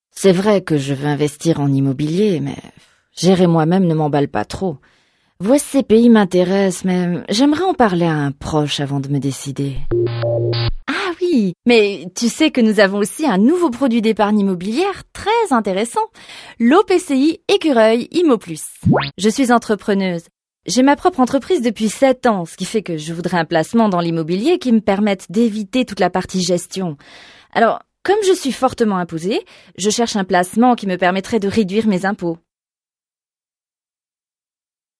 Découvrez une voix féminine aux timbres multiples : douce et élégante pour les documentaires et narrations, pleine d’énergie et de sourire pour les utilisations commerciales, libre et fantaisiste pour créer les personnages de cartoon dont vous rêvez !
Sprechprobe: eLearning (Muttersprache):
I have a versatile voice, happy, full of energy and fun for commercials, soft and elegant for corporate and documentaries, and I love creating imaginary characters.